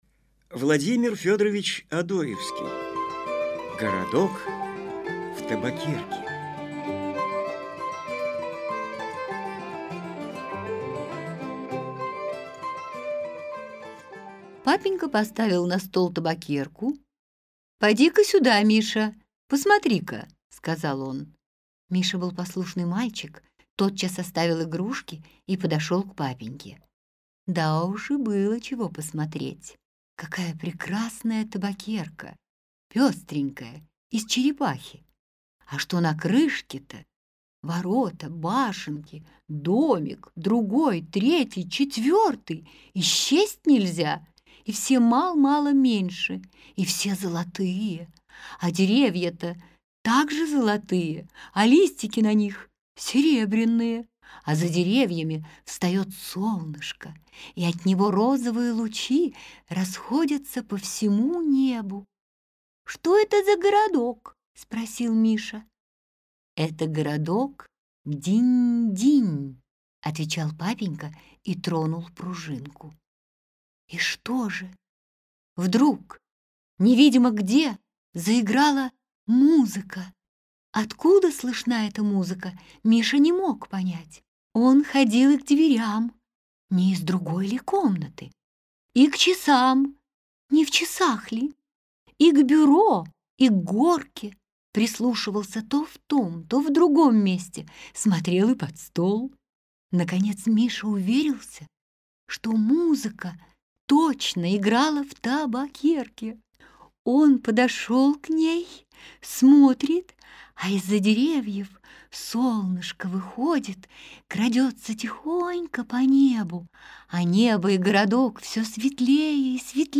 Аудиокнига Сказки русских писателей | Библиотека аудиокниг